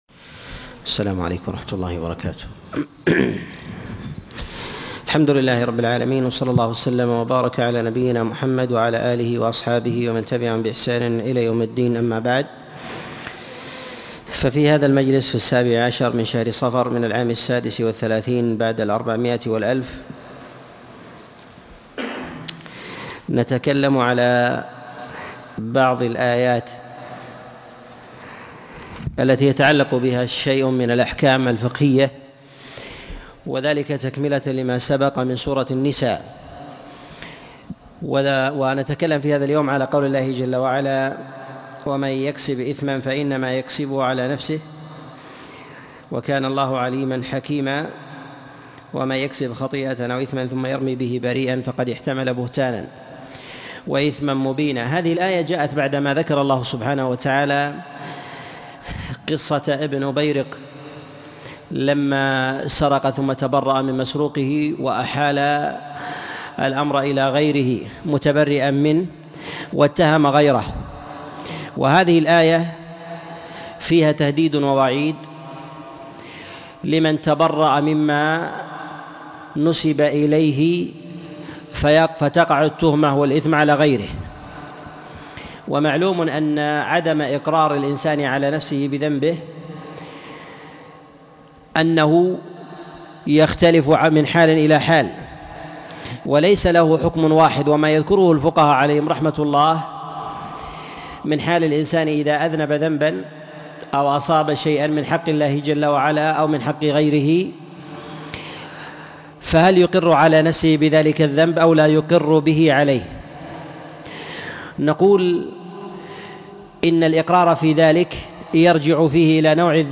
تفسير سورة النساء 26 - تفسير آيات الأحكام - الدرس الثمانون